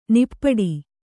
♪ nippaḍi